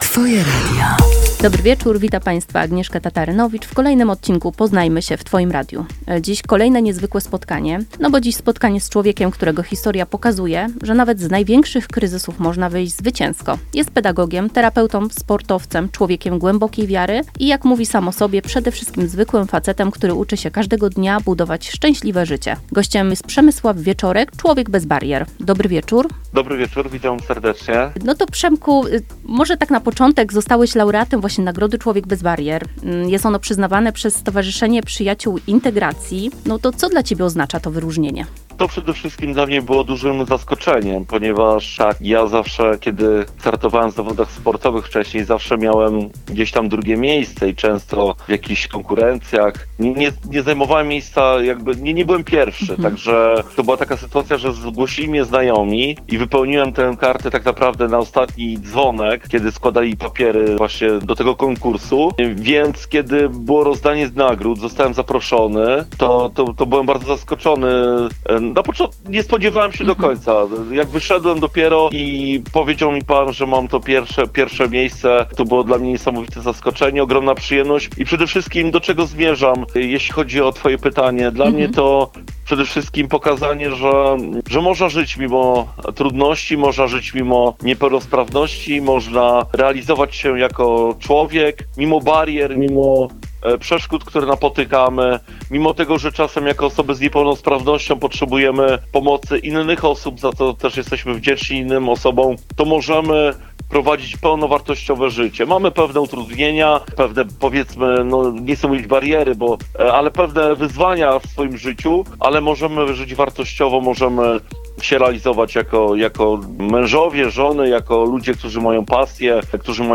W rozmowie usłyszycie o drodze przez trudności, roli wiary, sile sportu, pracy z ludźmi i o tym, jak pasja i wiara może stać się fundamentem szczęśliwego życia.